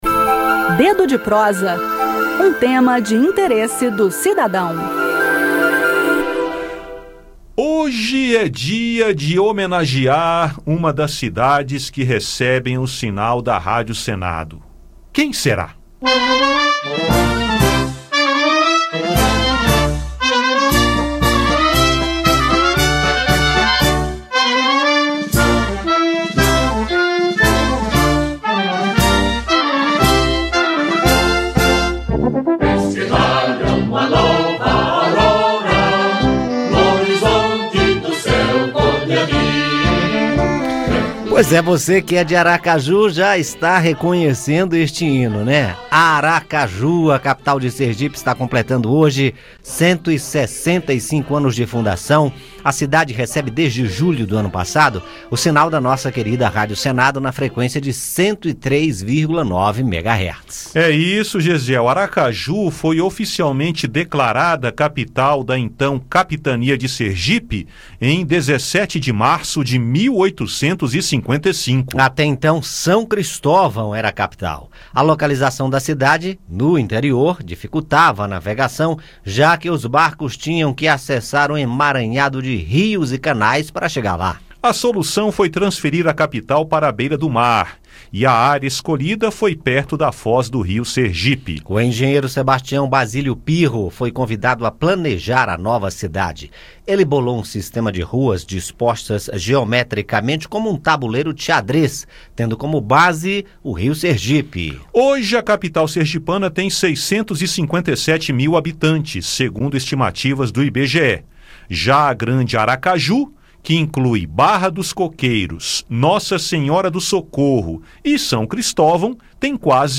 No "Dedo de Prosa" desta terça-feira (17), o assunto é o aniversário de 165 anos de fundação da cidade de Aracaju, oficialmente declarada capital da então Capitania de Sergipe em 17 de março de 1855. Ouça o áudio com o bate-papo.